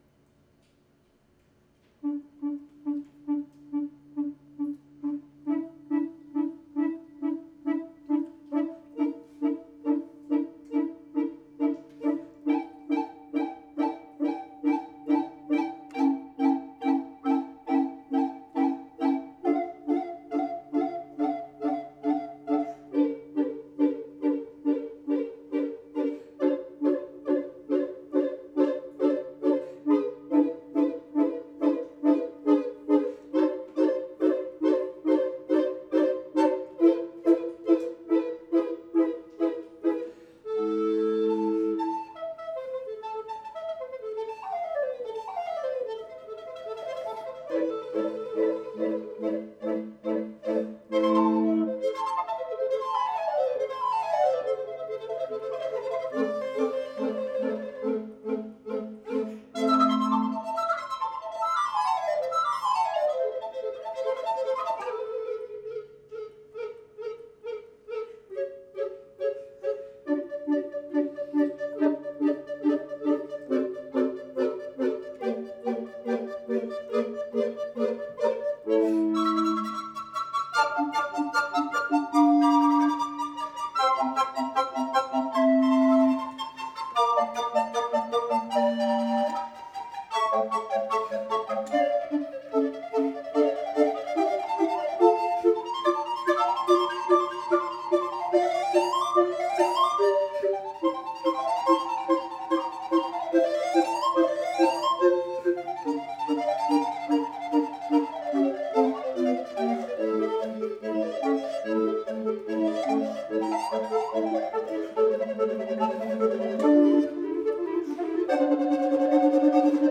J'écoute et je crée - L'invierno de Vivaldi - Quatuor Flûte Alors
linvierno-de-vivaldi-quatuor-flute-alors.wav